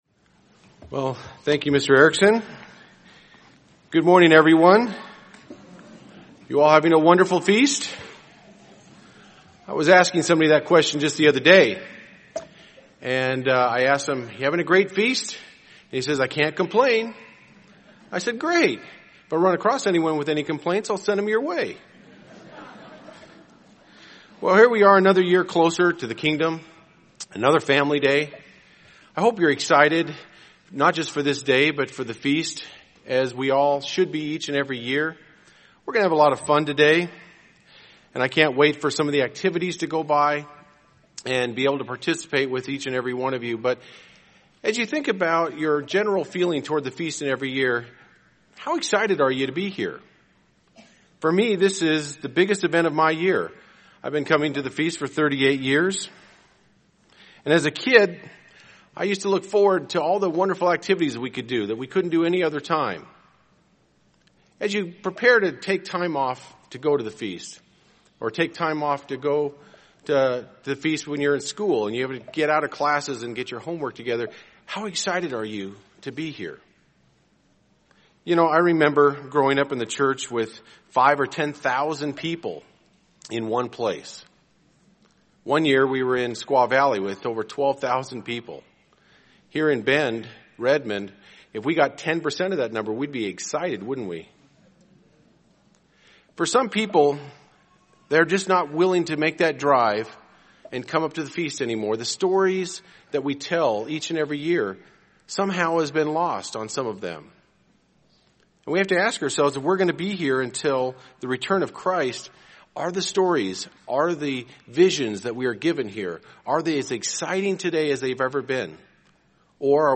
This sermon was given at the Bend, Oregon 2013 Feast site.